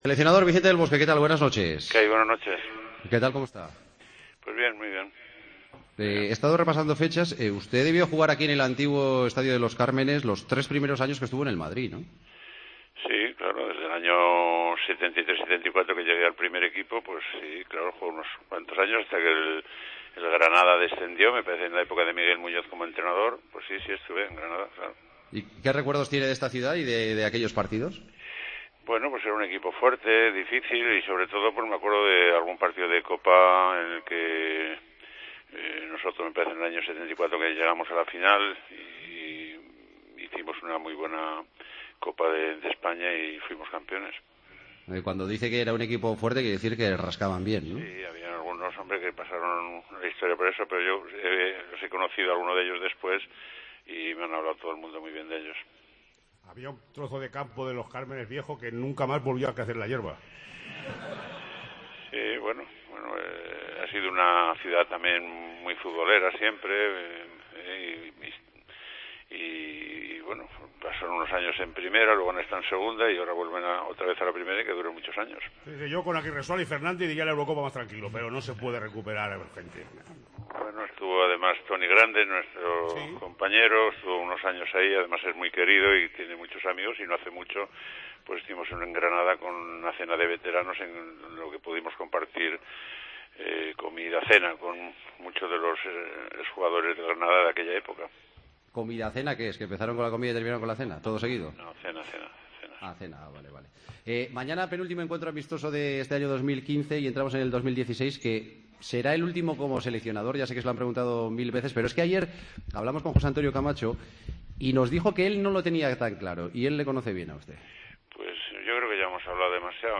AUDIO: Entrevistamos al seleccionador nacional de fútbol el día previo al amistoso entre España e Inglaterra en Alicante: "Tenemos seis...